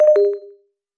14. wifi disconnected